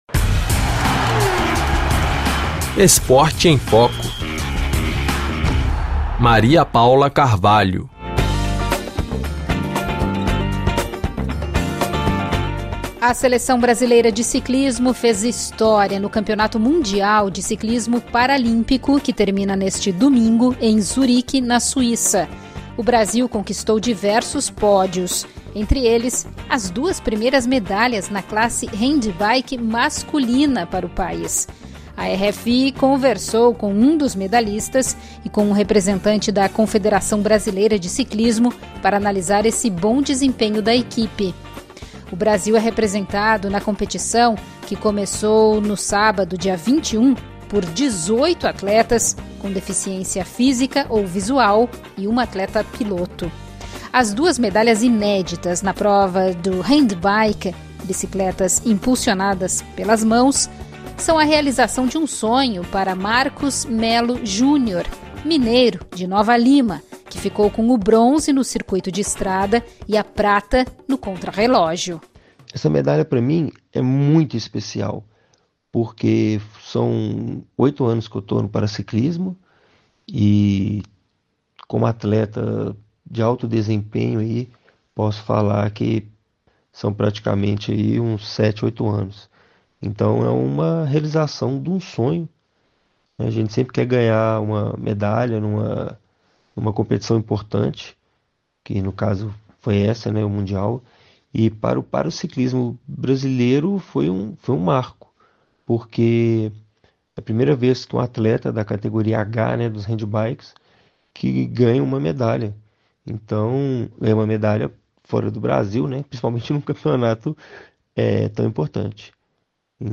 A RFI Brasil conversou com um dos medalhistas para analisar esse bom desempenho da equ…